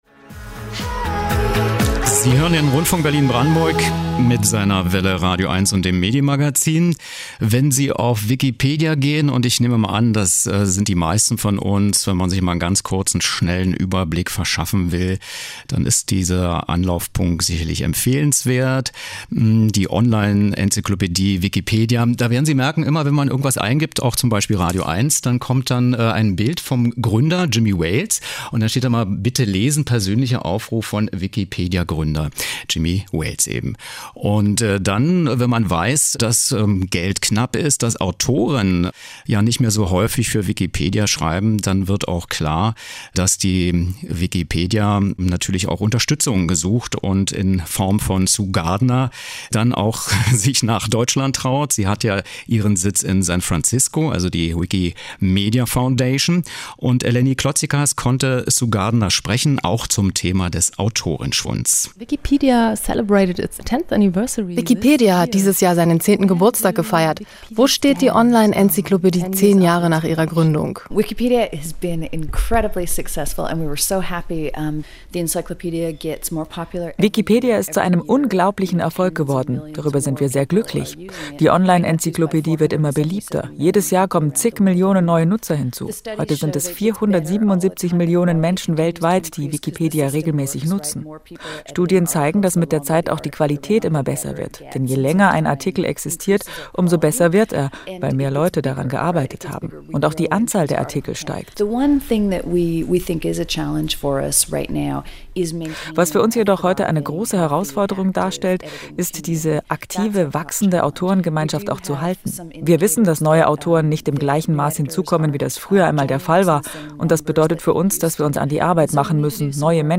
* Sue Gardner, Executive Director der Wikimedia Foundation
Was: Interview zu Wikipedia nach 10 Jahren der Gründung
Wo: Berlin